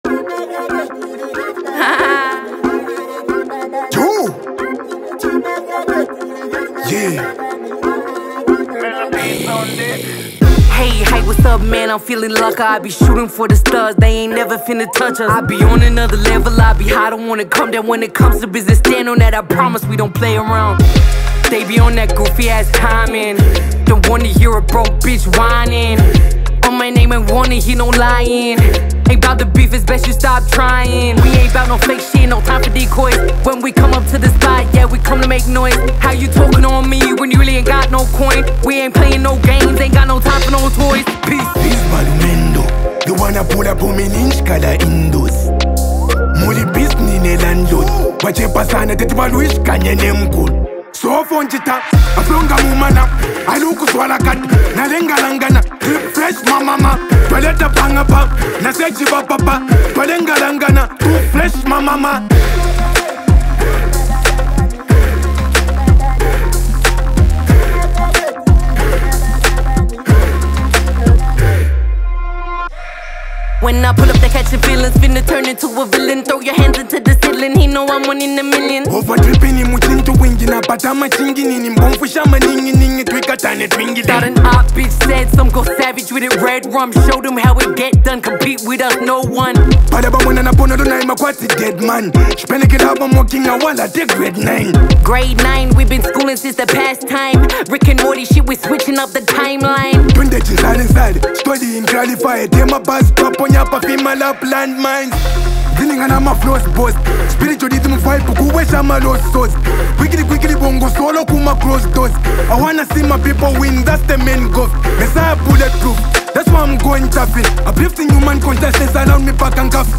infectious rhythm